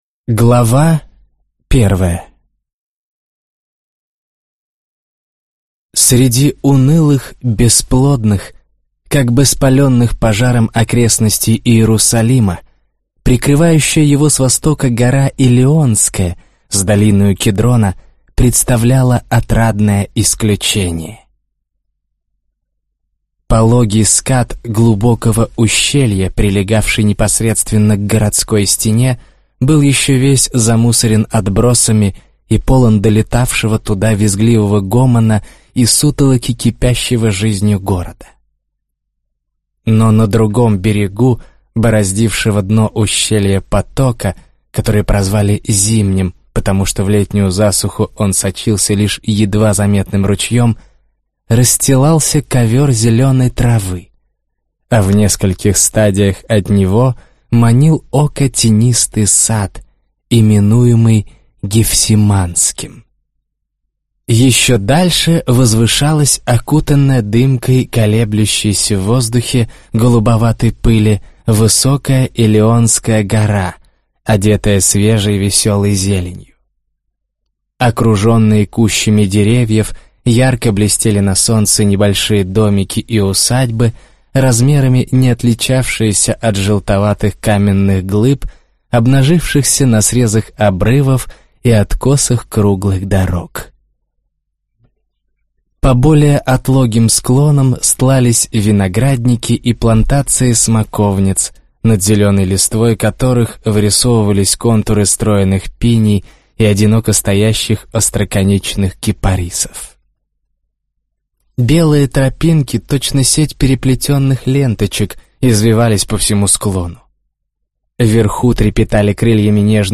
Аудиокнига Мария Магдалина | Библиотека аудиокниг